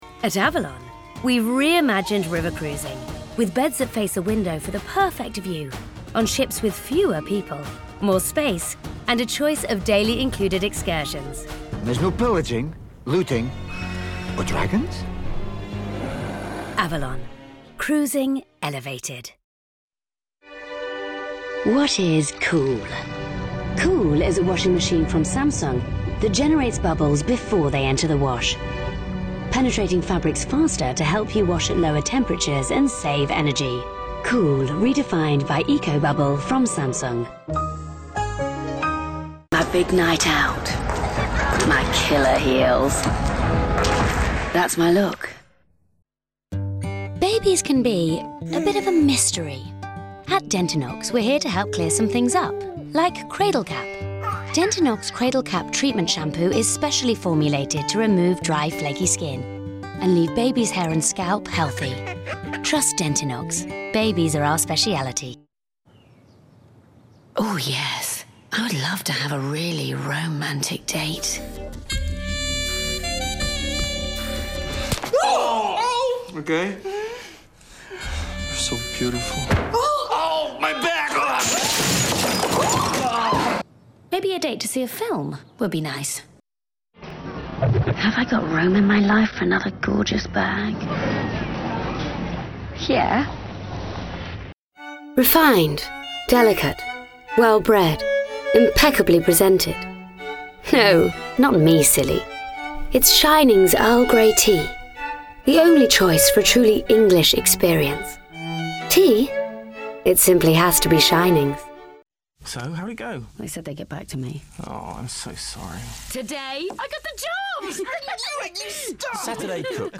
Warm but clear voice with a slightly husky quality and a natural humour. Good characterisation - from party girls to young mums.